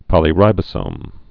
(pŏlē-rībə-sōm)